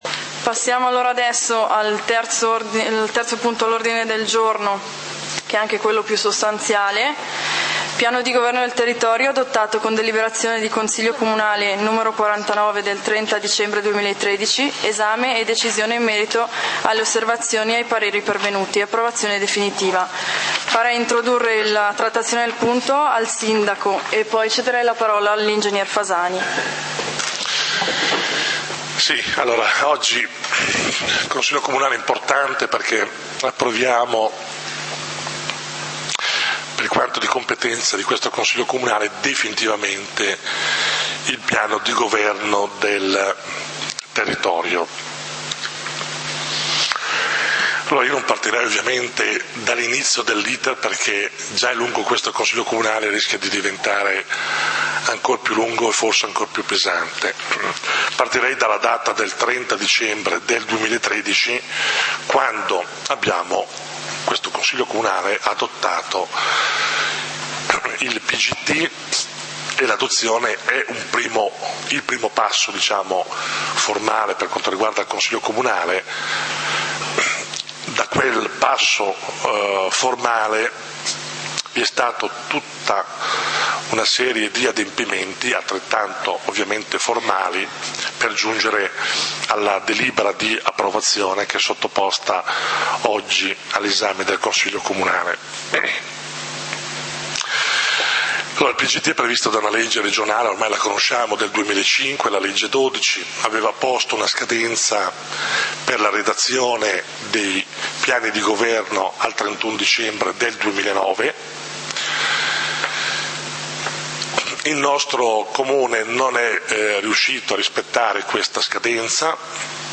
Consiglio comunale di Valdidentro del 30 Giugno 2014
Consiglio comunale del 30 Giugno 2014 torna alla lista dei punti Punto 3a: Piano di governo del territorio adottato con deliberazione di consiglio comunale n. 49 del 30.12.2013. Esame e decisione in merito alle osservazioni e ai pareri pervenuti. Approvazione definitiva; Intervento del Sindaco Ezio Trabucchi.